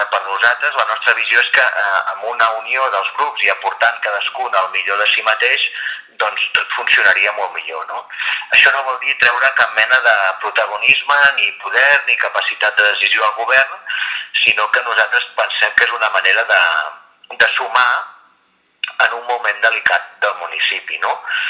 El seu regidor, Lluís Casas, creu que “era una bona oportunitat per aconseguir refer ponts entre el govern i tota l’oposició” i coincideix que la situació és “delicada”. En tot cas, en referència al pacte de legislatura i estabilitat firmat amb l’actual Executiu, manté que “no canvia res” i que “tot continua igual”: